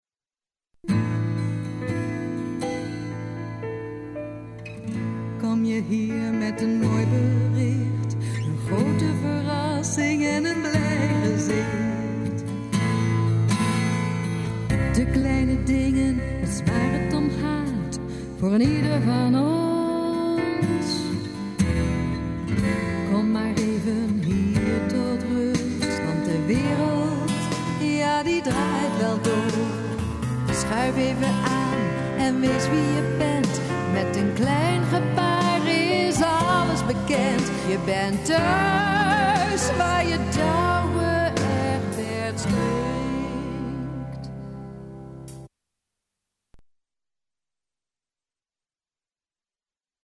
muziektheater
Nederlandstalig, jazzy  en theatraal.